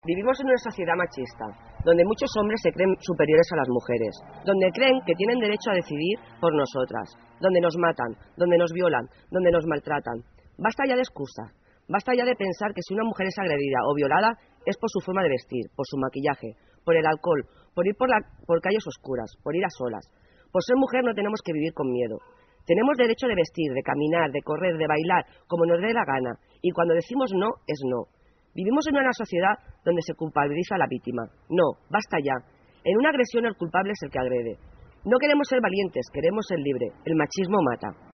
El vespre d’aquest dijous, la plaça de l’Ajuntament de Palafolls va tornar a ser l’escenari d’una nova concentració de protesta contra la violència masclista.
Durant el manifest, els convocants van voler remarcar que es viu en una societat “masclista”, on molts homes es creuen superiors a les dones.